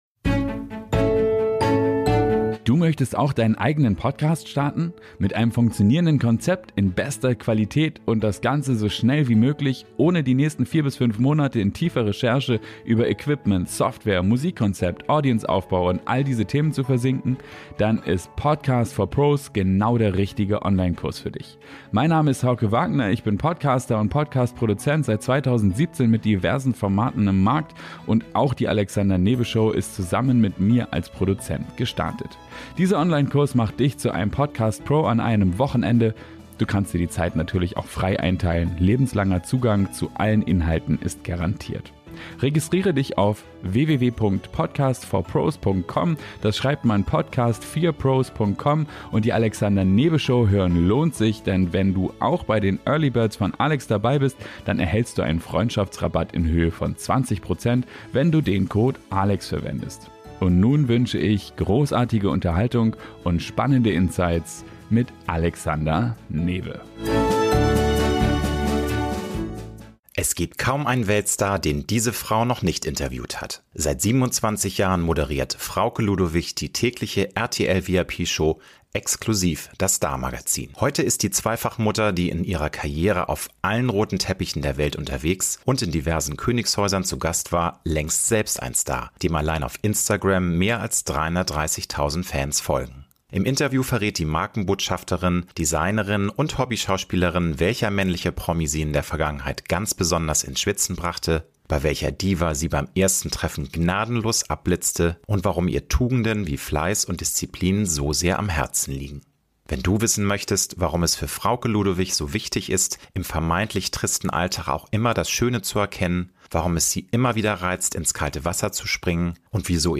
Promi-Talk